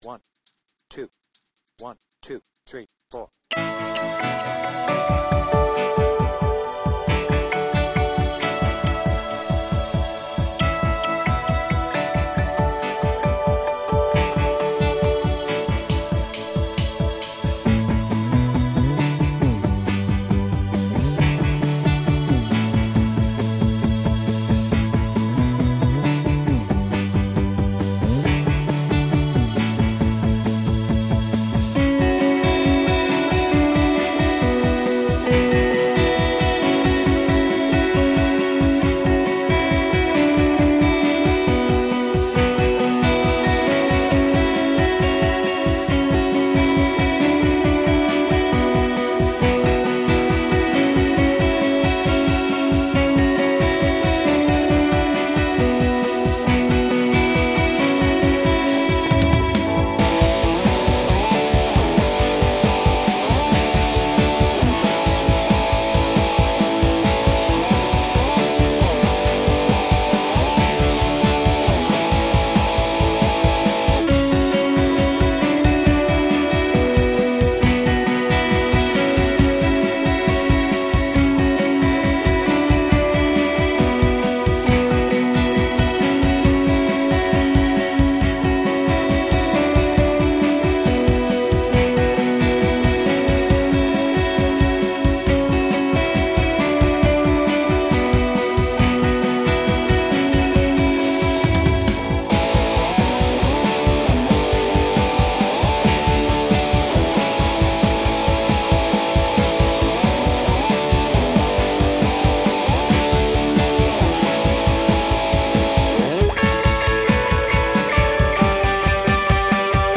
BPM : 136
Without vocals